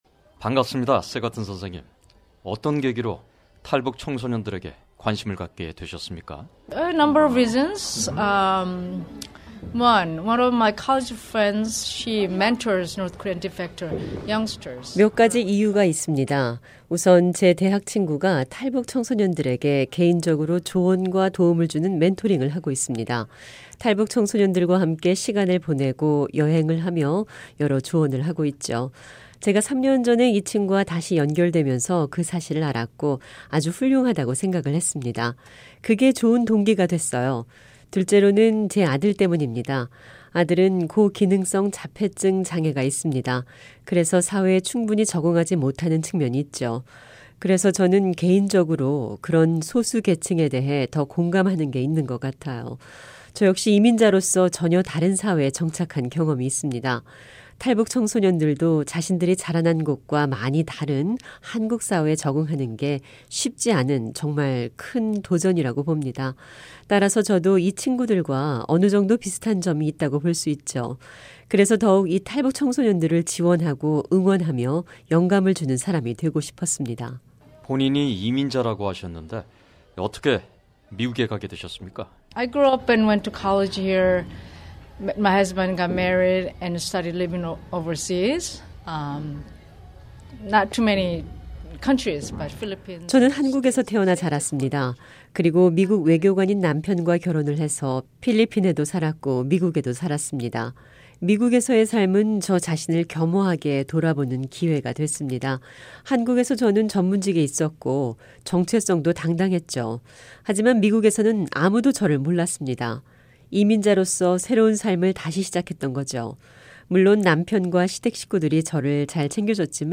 특파원 리포트